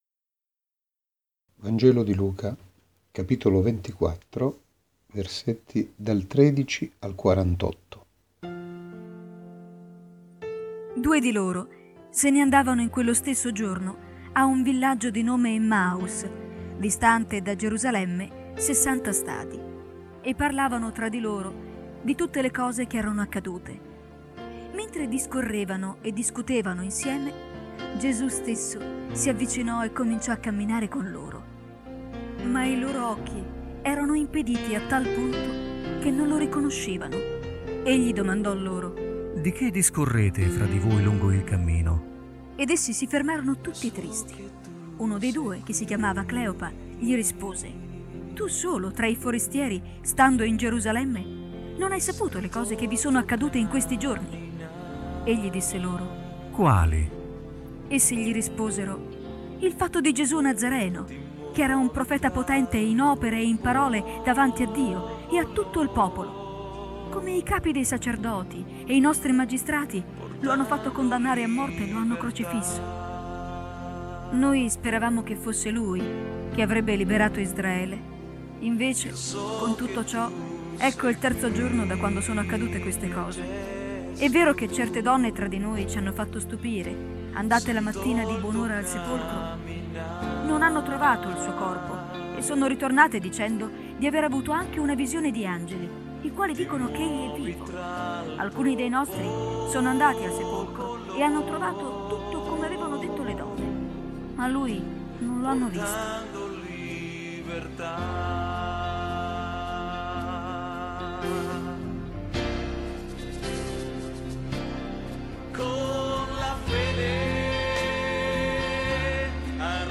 Letture della Parola di Dio ai culti della domenica